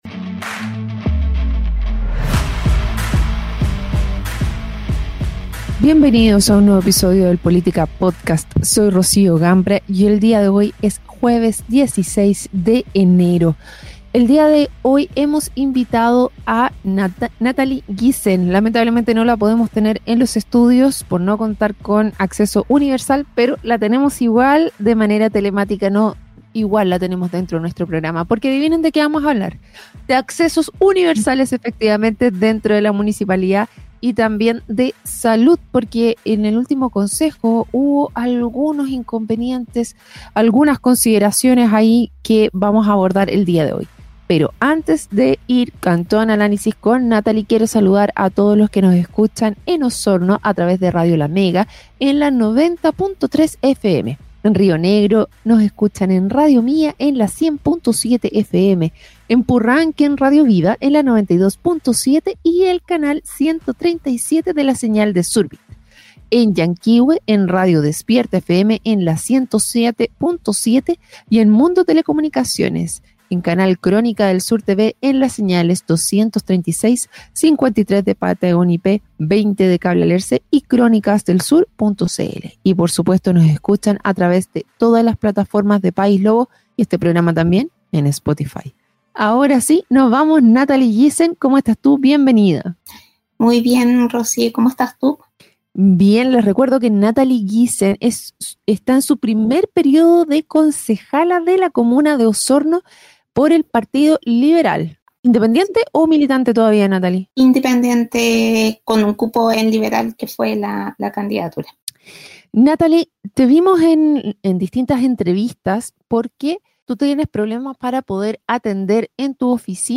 La concejala de Osorno, Natalie Gissen, ha levantado la voz frente a las dificultades de accesibilidad universal en las dependencias municipales. En una reciente entrevista, expuso las deficiencias en infraestructura que afectan no solo a su labor, sino también a las personas con discapacidad que buscan atención en el consistorio.